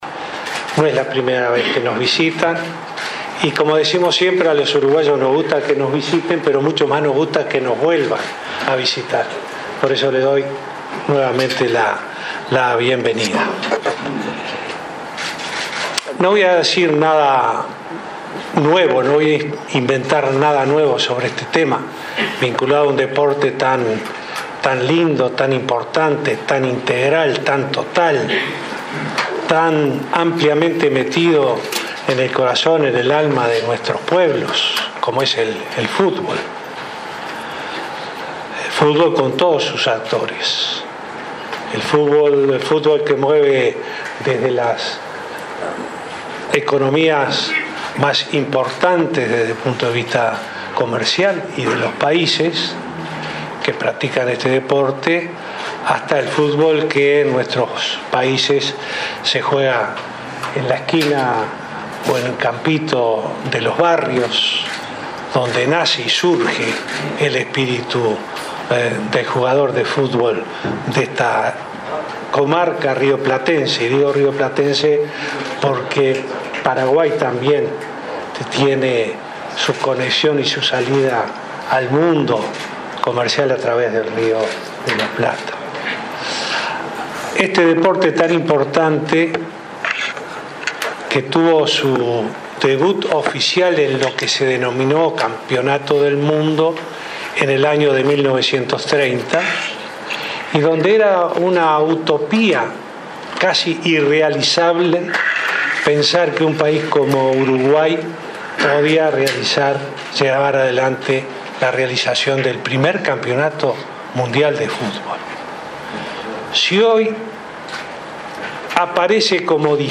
“Cuando se cumplen cien años del primer campeonato del mundo no puede haber nada más justo que el fútbol mundial reconozca aquella gesta histórica”, subrayó el presidente Vázquez en la reunión de la comisión que trabaja en la postulación de Uruguay, Argentina y Paraguay para organizar el mundial 2030. Destacó las buenas comunicaciones y la hotelería de Uruguay, y dijo que la comisión tiene el respaldo de los tres gobiernos.